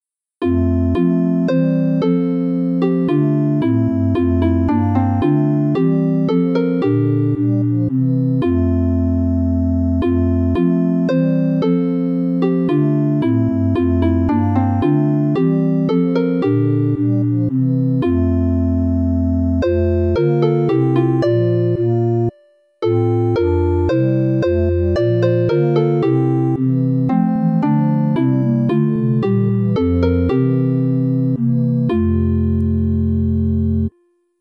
Orgelchoral: vierstimmiger Satz von Rudolf Mauersberger, © Bärenreiter-Verlag
Nach J. S. Bach variierte Melodie mit Harfenklang